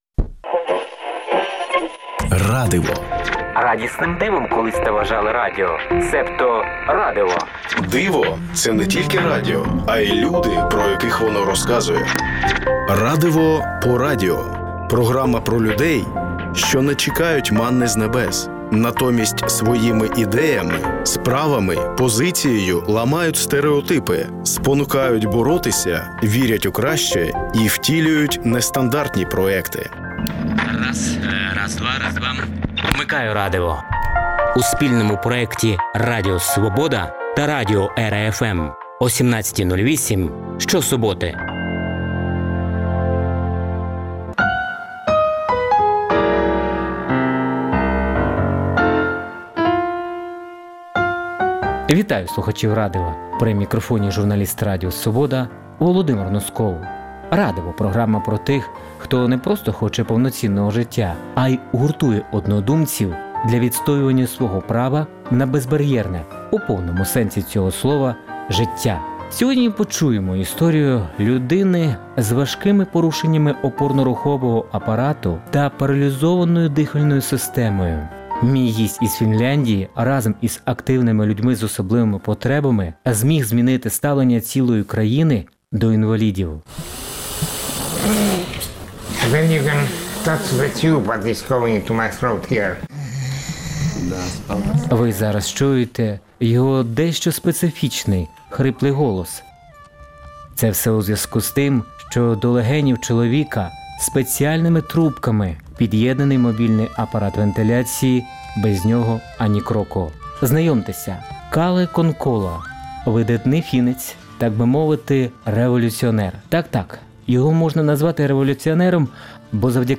«Радиво» по радіо – це спільний проект Радіо Свобода та радіо «ЕРА-Fm» що присвячується людям, які не чекають манни з небес, натомість своїми ідеями, справами, позицією ламають стериотипи, спонукають боротися, вірять у краще і втілюють нестандартні проекти. Вмикайте «Радиво» що-суботи О 17.08. В передачі звучатимуть інтерв’ю портретні та радіо замальовки про волонтерів, військових, вчителів, медиків, громадських активістів, переселенців, людей з особливими потребами тощо.